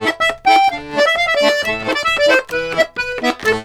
Index of /90_sSampleCDs/USB Soundscan vol.40 - Complete Accordions [AKAI] 1CD/Partition C/04-130POLKA
C130POLKA2-R.wav